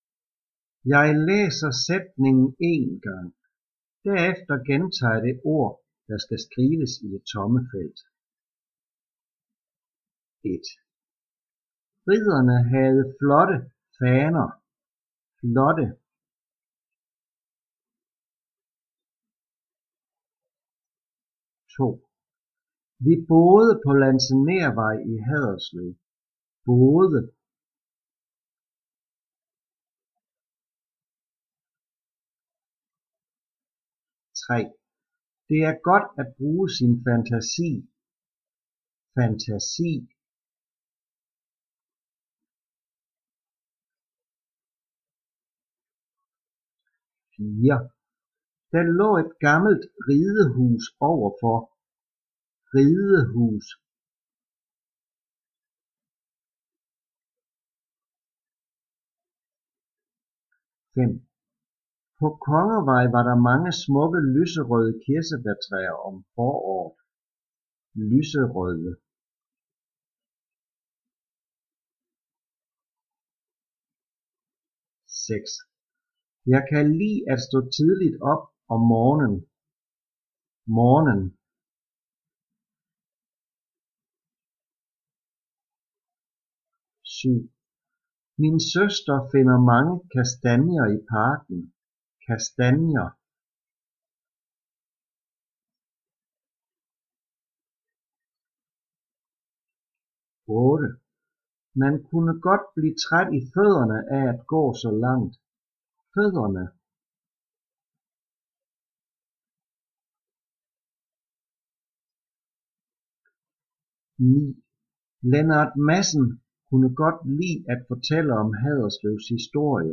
Trin 3 - Lektion 1 - Diktat
Jeg læser sætningen én gang. Derefter gentager jeg det ord, der skal skrives i det tomme felt.